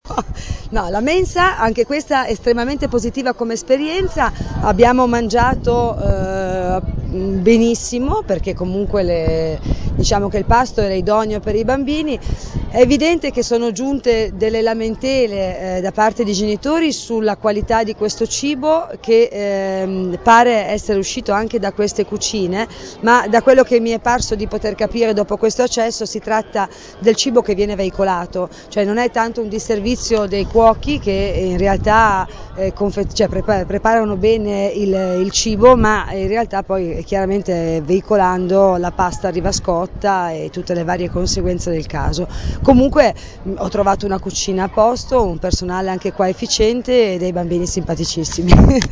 Intervista Berlinguer Mense (382 kB)
Intervista_Berlinguer_Mense.MP3